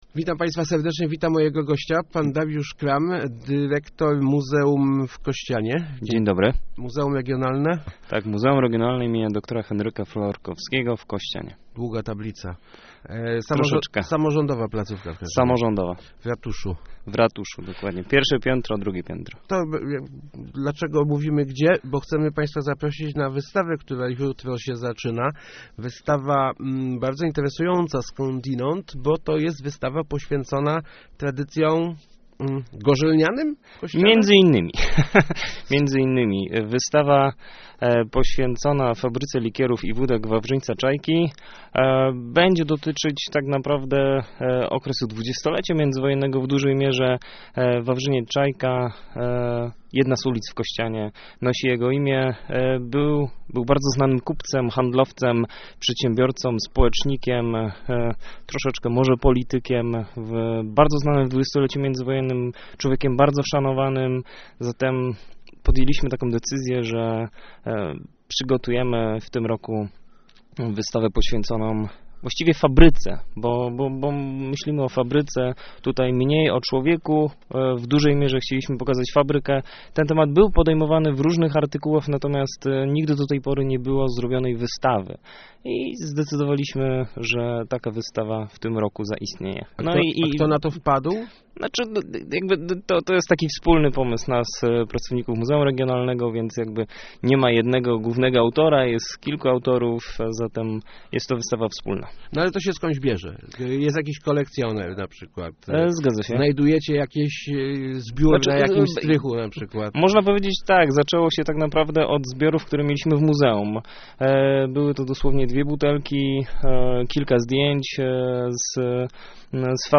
Start arrow Rozmowy Elki arrow Iwan, co Kościan rozsławiał